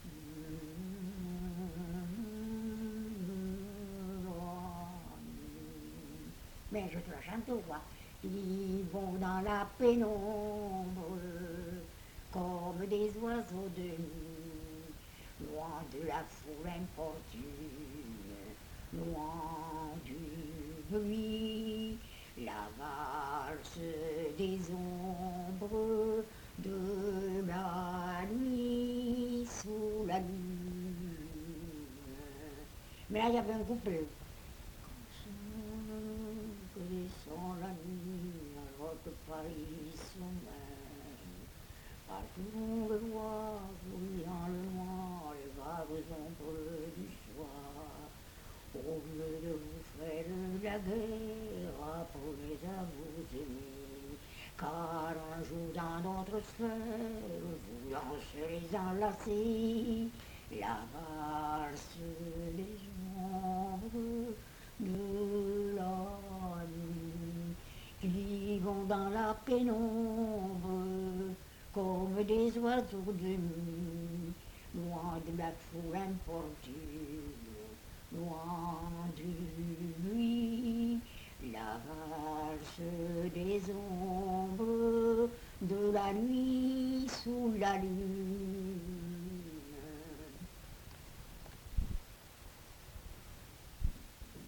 Lieu : Mas-Cabardès
Genre : chant
Type de voix : voix de femme
Production du son : chanté
Danse : valse
Description de l'item : fragment ; 1 c. ; refr.